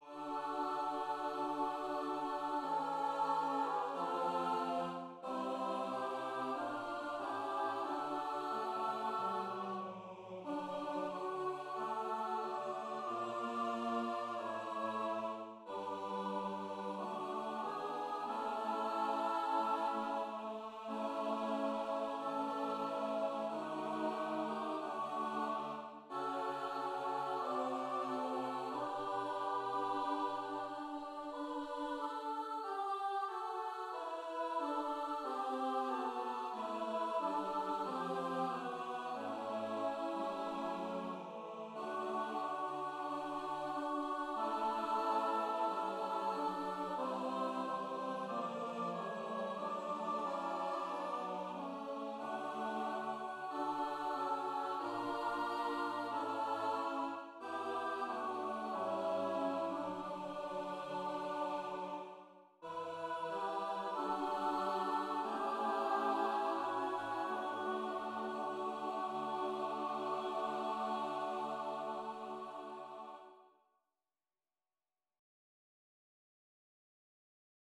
Voicing/Instrumentation: SATB We also have other 2 arrangements of " Onward, Christian Soldiers ".